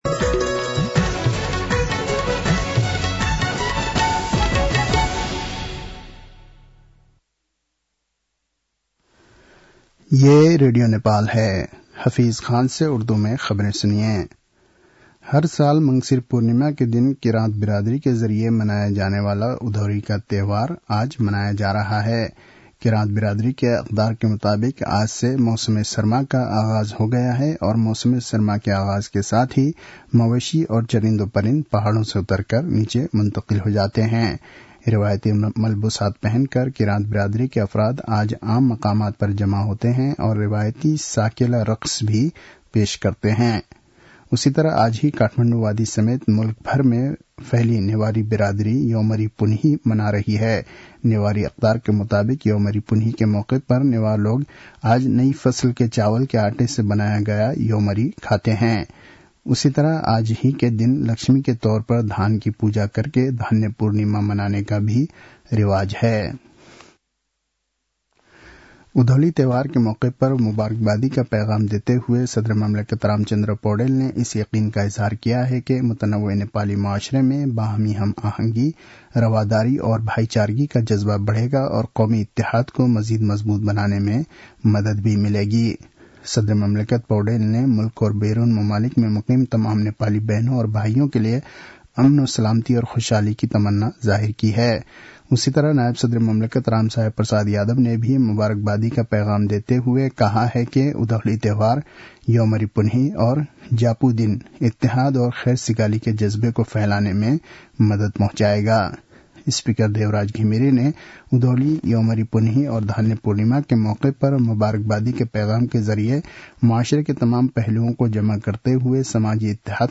उर्दु भाषामा समाचार : १ पुष , २०८१